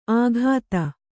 ♪ āghāta